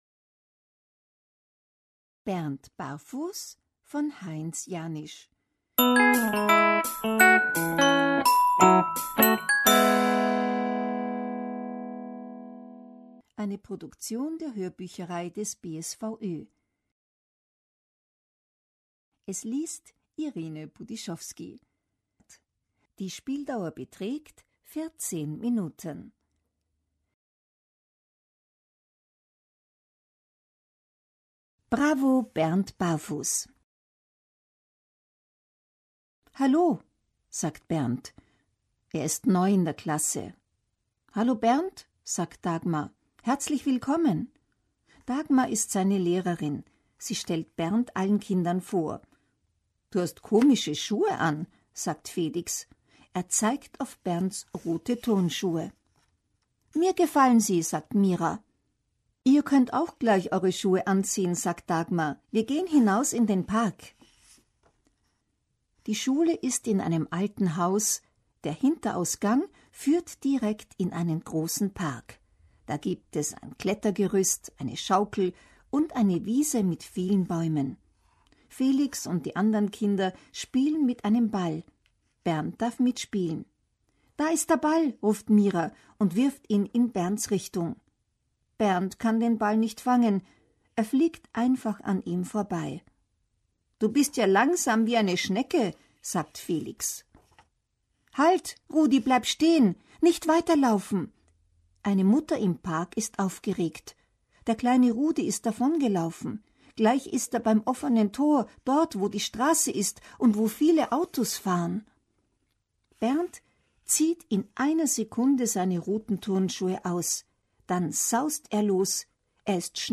1179_Hoerbuch_Hoerbuecherei.mp3